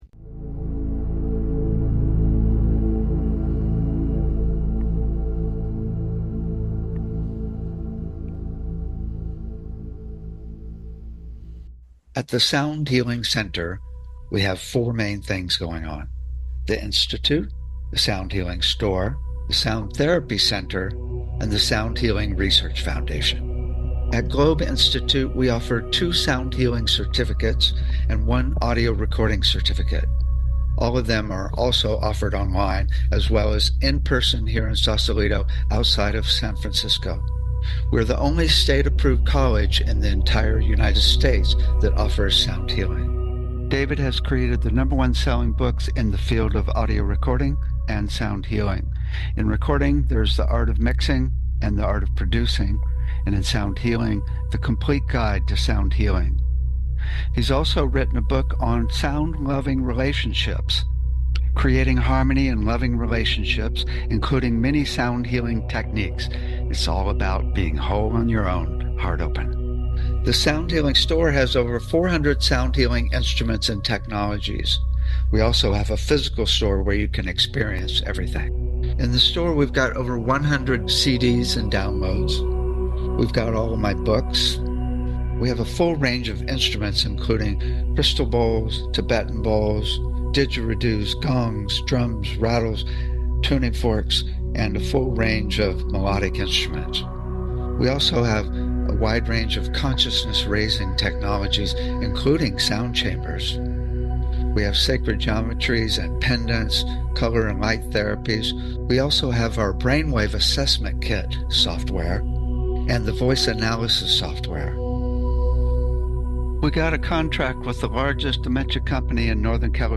Talk Show Episode, Audio Podcast, Sound Healing and The Hierarchy of Vibrations on , show guests , about The Hierarchy if Vibrations,Sound Healing,Frequency,Timbre,Musical Intervals,flow,complexities of healing, categorized as Health & Lifestyle,Energy Healing,Sound Healing,Kids & Family,Emotional Health and Freedom,Mental Health,Science,Self Help,Spiritual